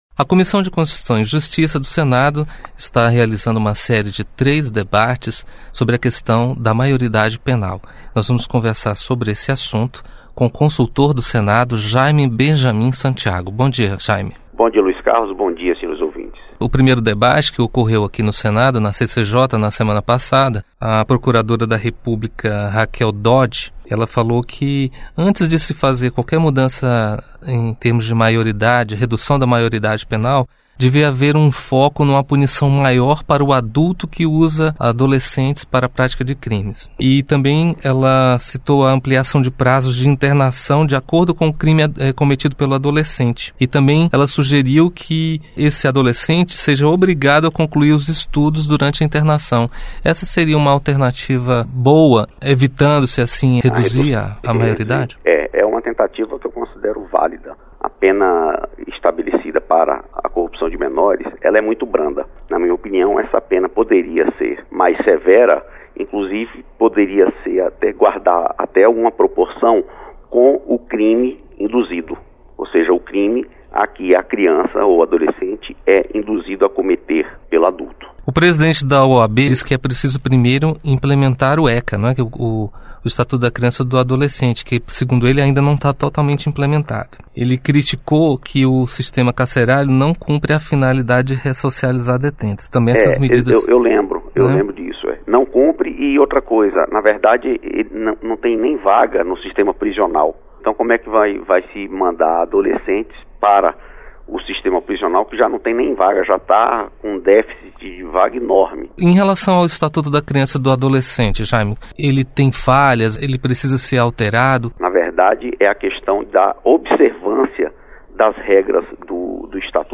Entrevista: Redução da maioridade penal